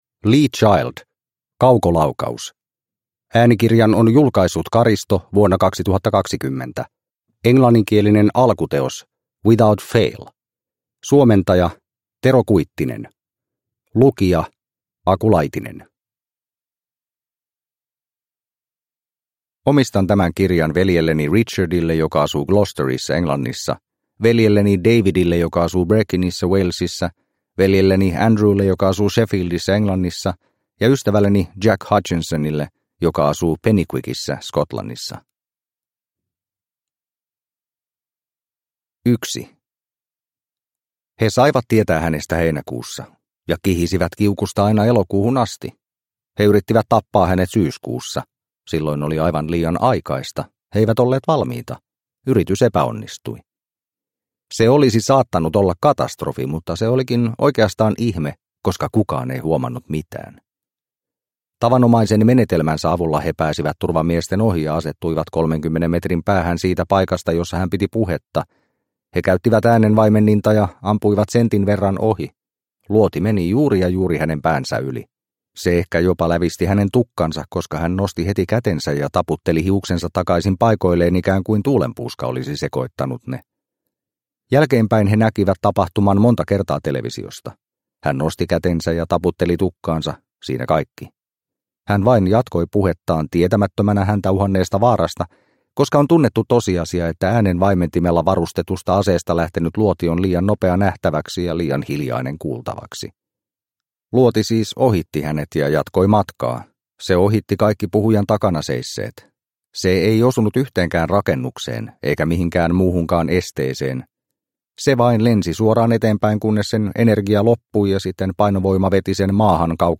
Kaukolaukaus – Ljudbok – Laddas ner